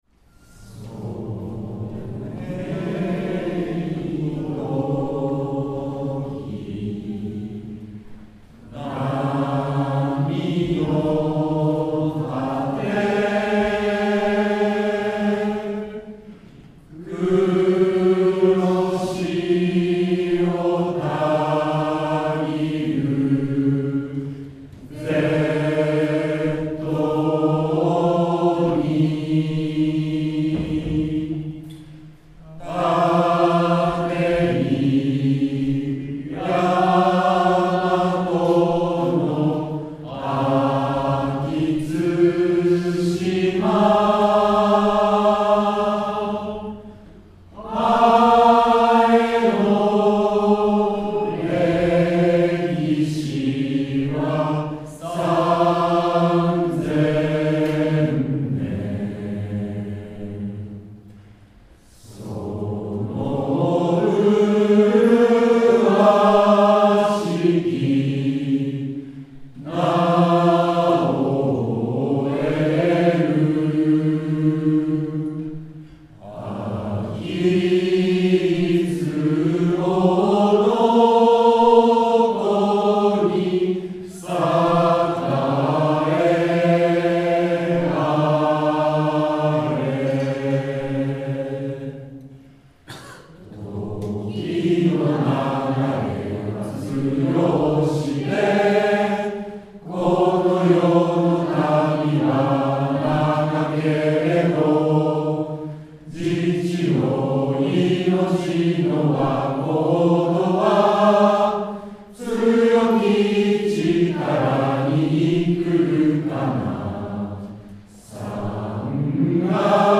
卒業30周年記念事業 記念式典
校歌斉唱